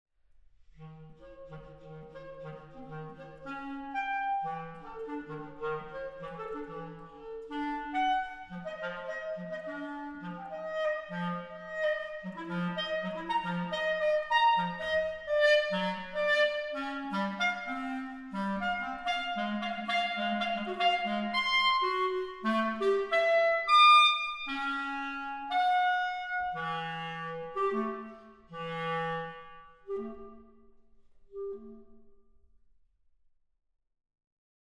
soprano
clarinet
piano